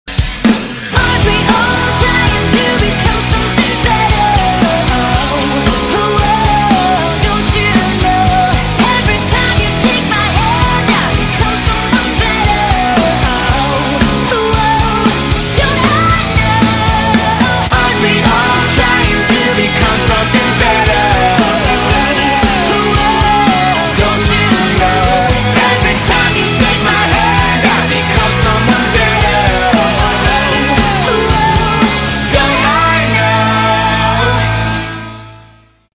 • Качество: 192, Stereo
Яркий и заводной трек